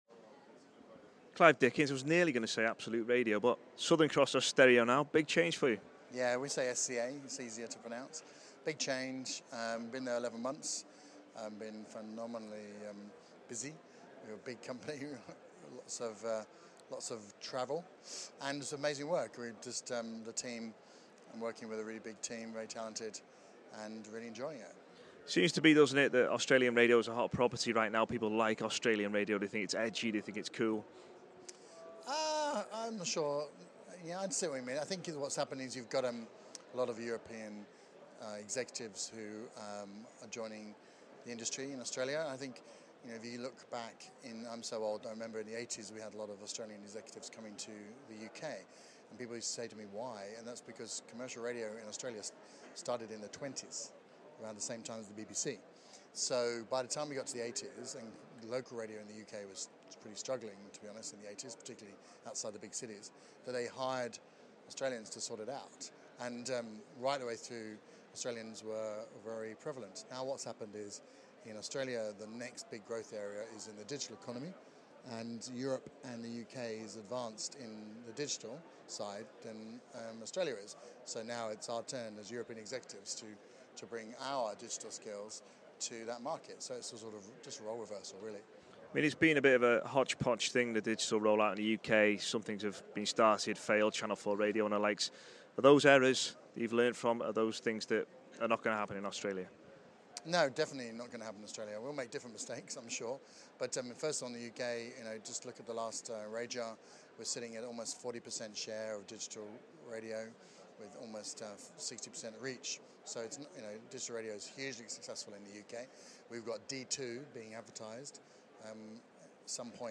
RadioToday Live Interviews